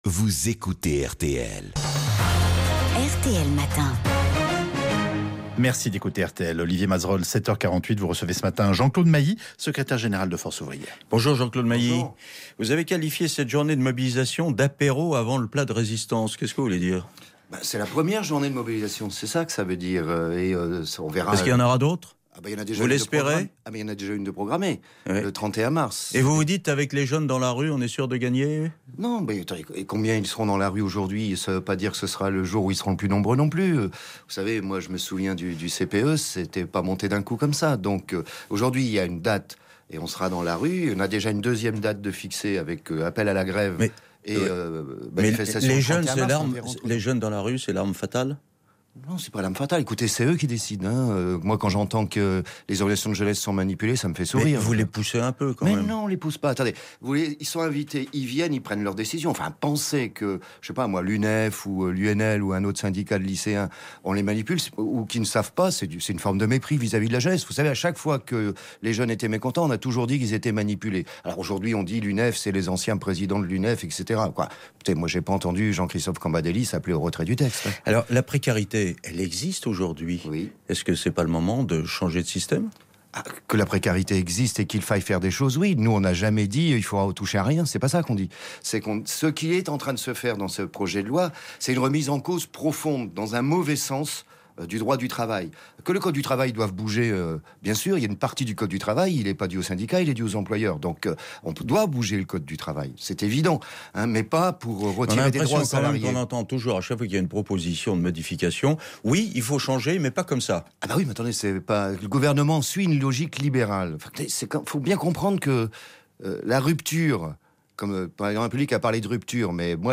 Invité de RTL ce mercredi 9 mars 2016, Jean-Claude Mailly, Secrétaire général de Force Ouvrière s’attend à une forte mobilisation des salariés en grève ce 9 mars.